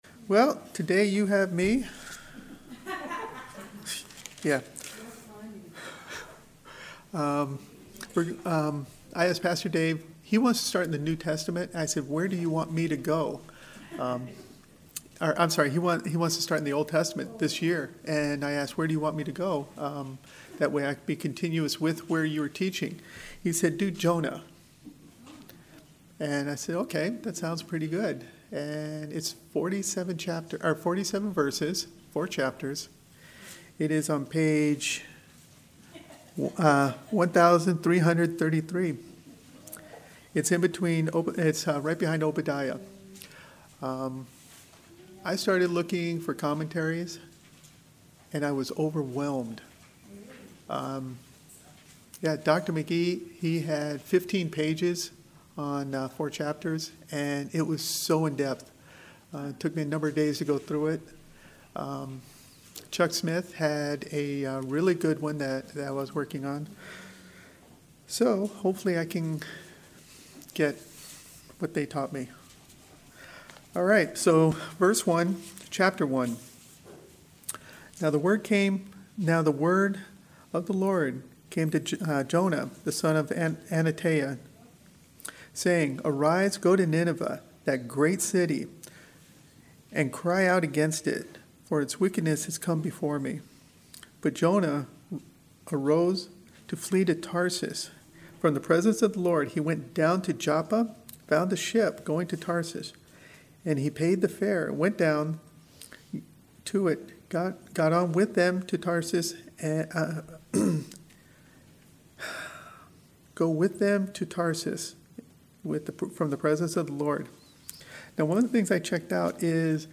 Sermons | Calvary Chapel Lighthouse Fellowship